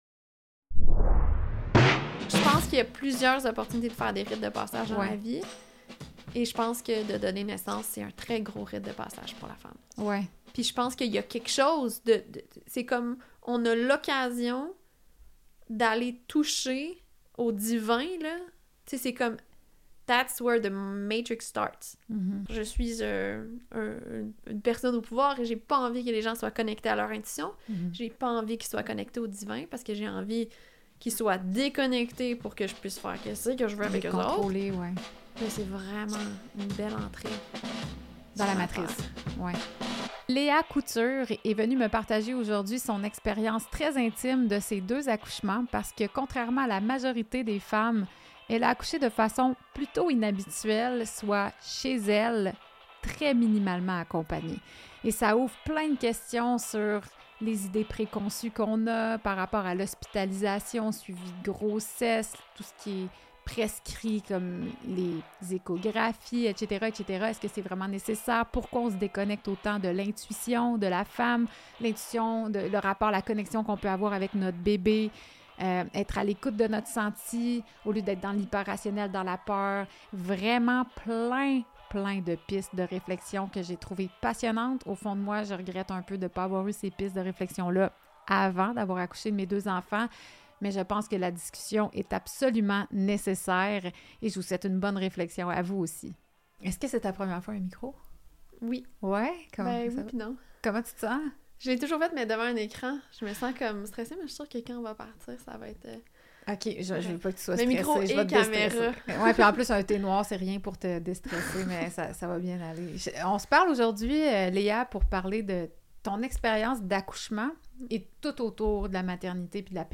Conférence publique